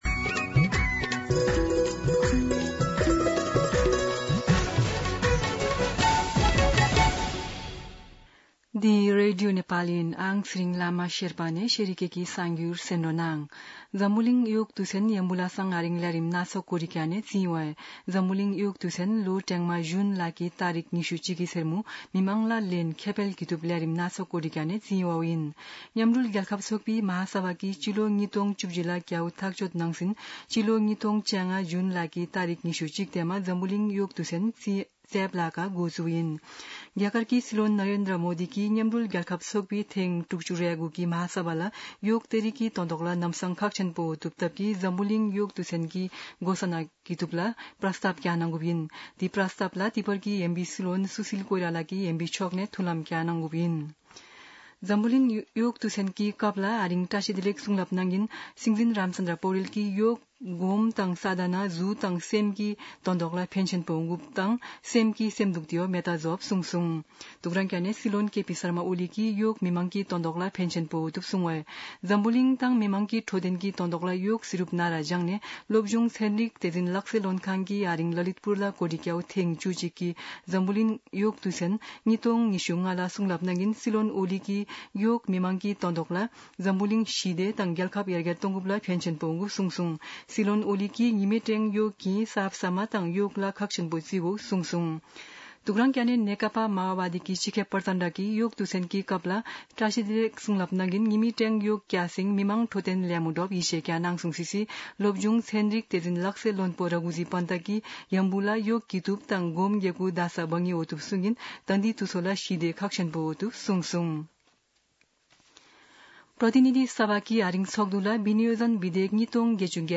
शेर्पा भाषाको समाचार : ७ असार , २०८२
Sherpa-News-07.mp3